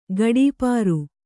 ♪ gaḍīpāru